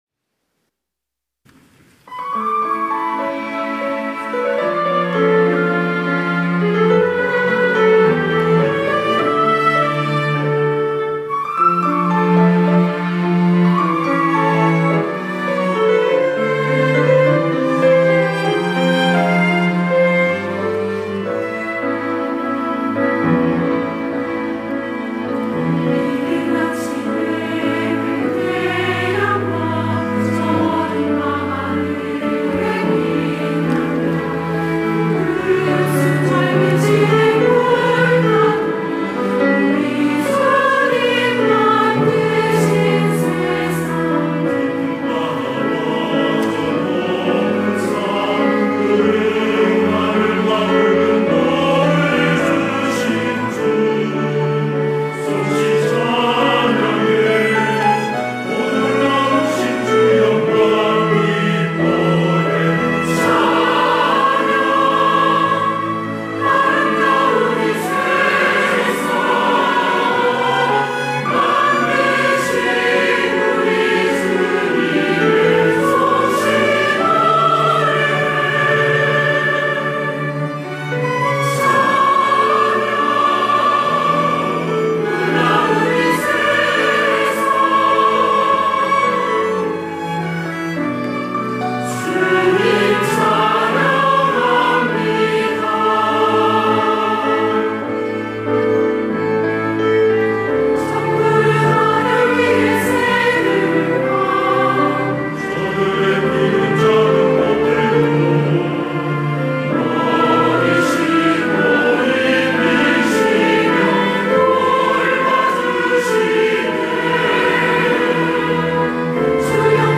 할렐루야(주일2부) - 주님 주신 세상
찬양대